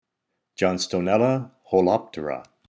Pronunciation/Pronunciación: John-sto-nél-la ho-lòp-te-ra Etymology/Etimología: "ribbed" Synonyms/Sinónimos: Homotypic Synonyms: Eritrichium holopterum A. Gray ( Basionym ), Proc.